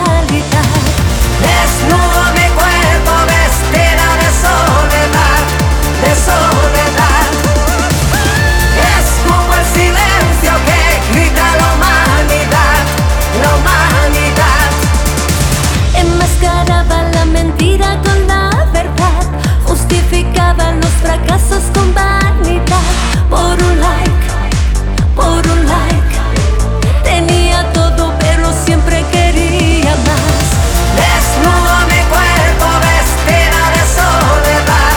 Жанр: R&B / Соул / Диско